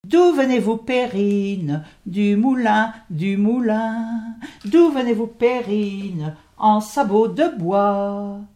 Couplets à danser
Pièce musicale inédite